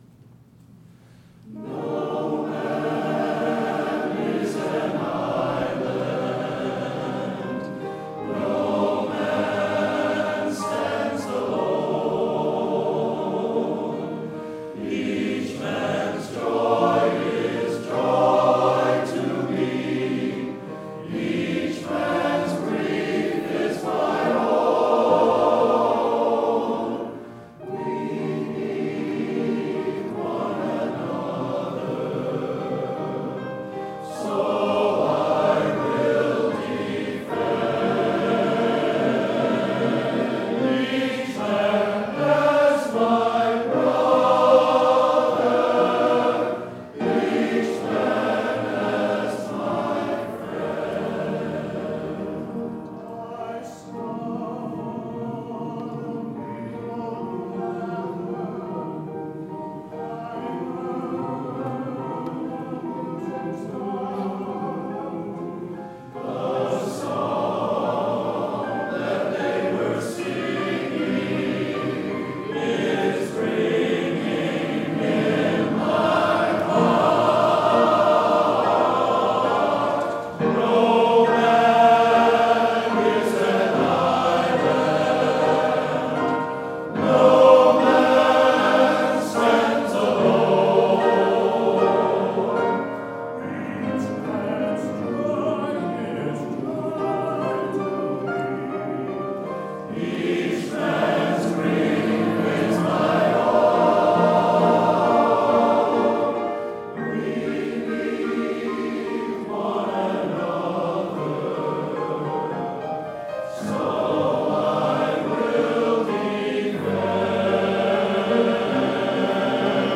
Barnstormers Fleetwood Concert, St. Paul's UCC, September 23, 2023